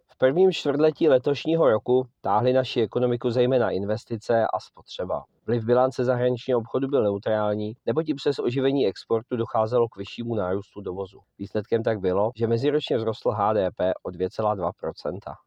Vyjádření Jaroslava Sixty, místopředsedy Českého statistického úřadu, soubor ve formátu MP3, 606.56 kB